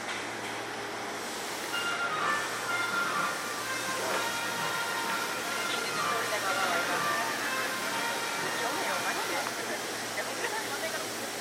発車メロディーも流れています。